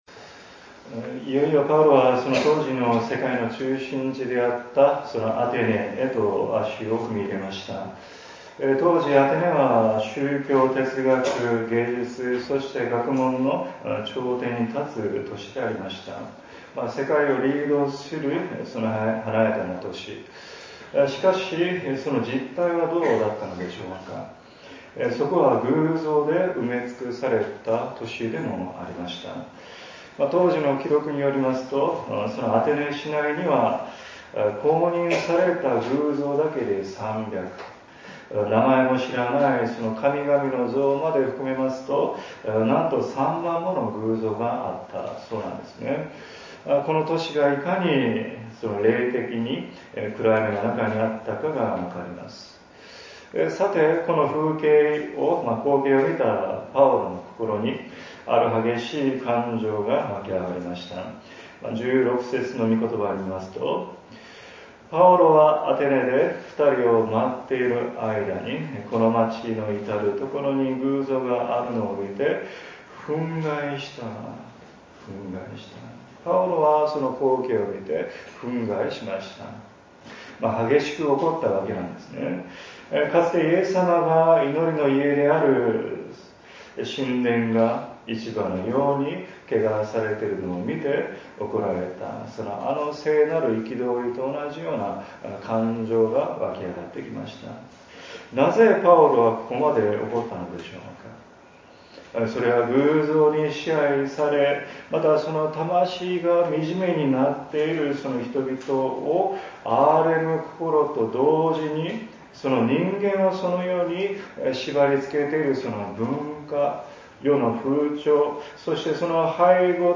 礼拝次第
説 教 「偶像の都市で叫ぶ福音」